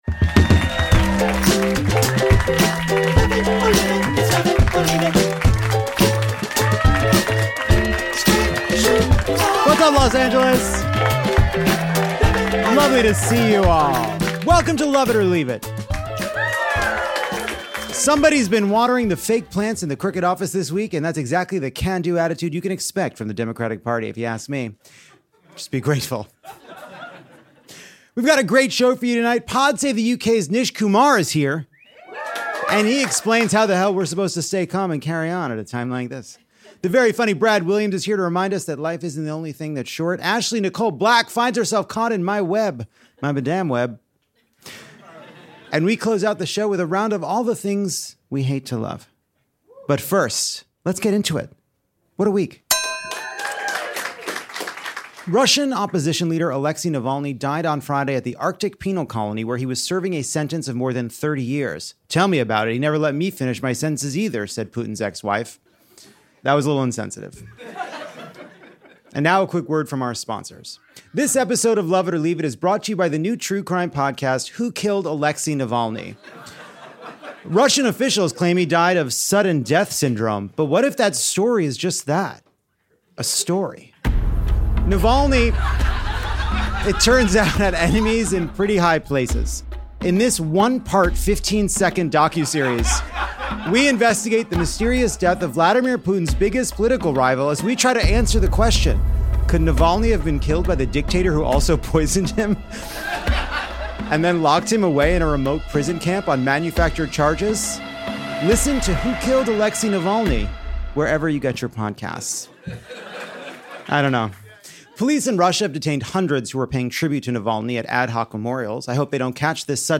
We’re back from studying spiders in the Amazon to bring you a fantastic new show, straight from L.A.’s Dynasty Typewriter.